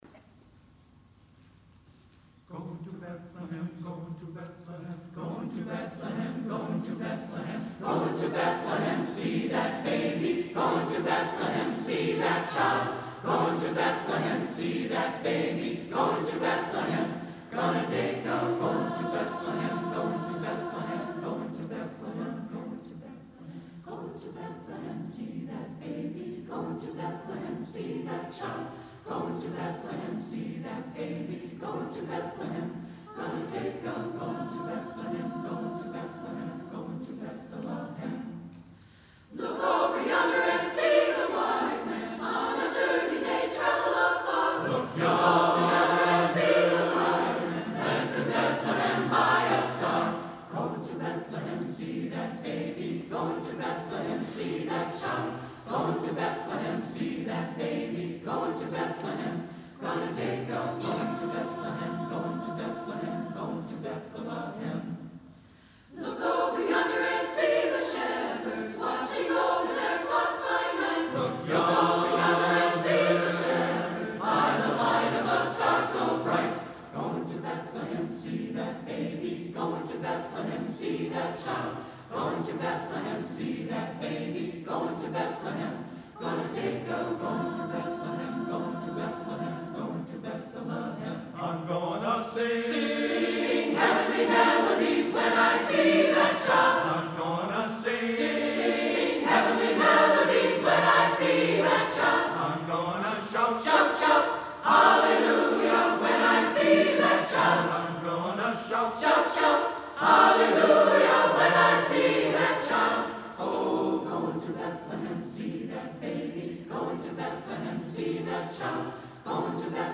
The Dickinson County Community Chorus
Auditorium, Kingsford High School, Kingsford, Michigan